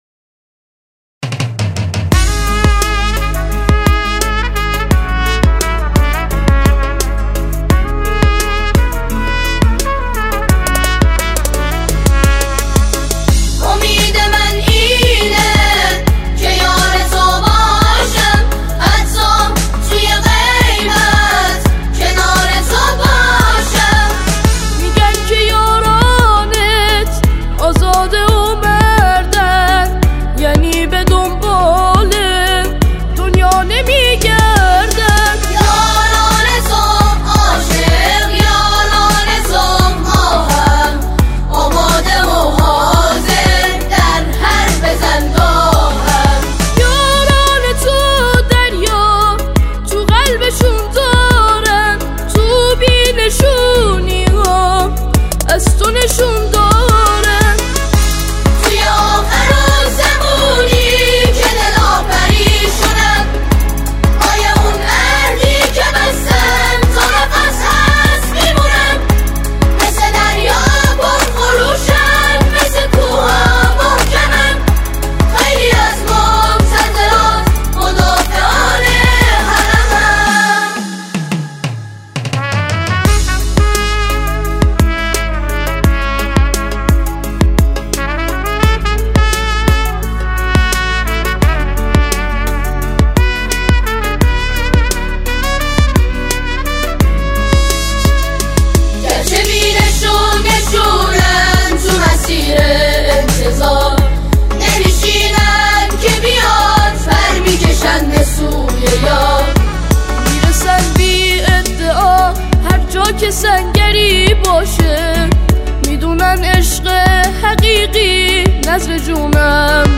در مسجد مقدس جمکران انجام شده است